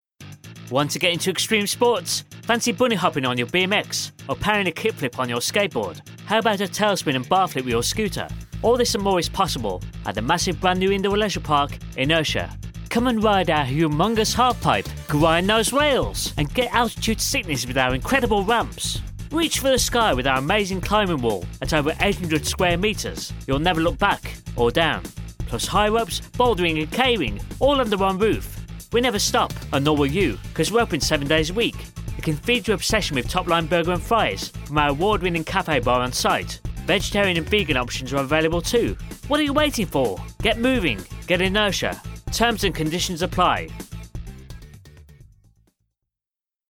A dynamic British male voice. Positive, friendly and sincere with subtle Midlands tones.
Radio Commercials